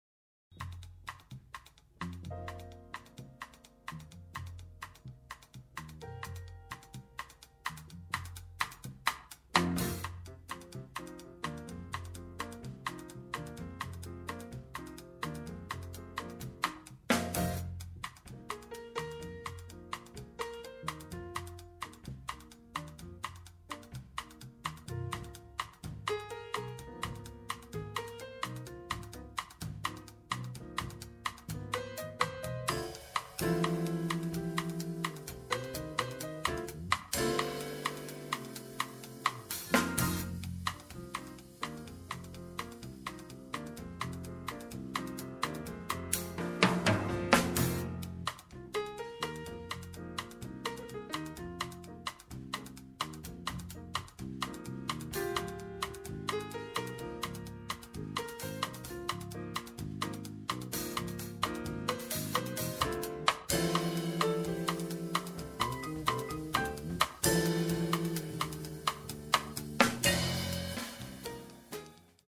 Détail combo: version trio: piano, basse et batterie.
version quartet: saxophone, piano, basse et batterie.